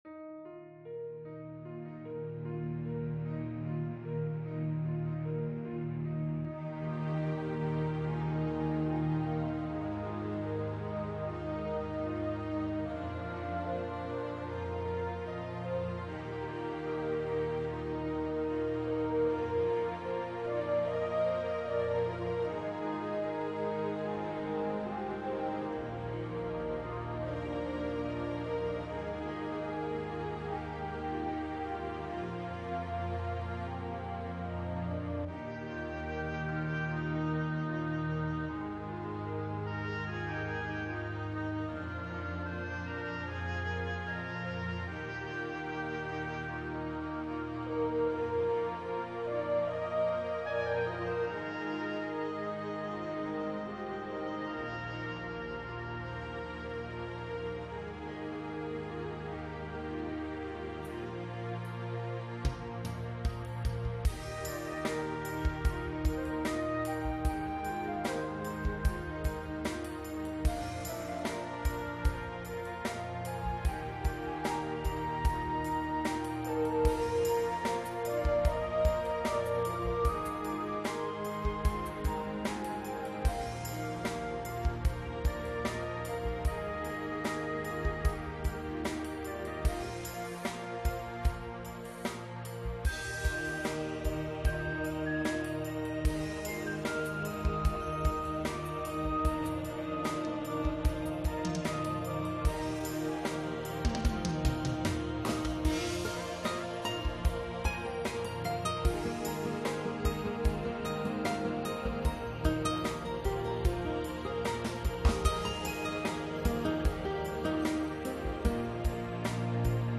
a remix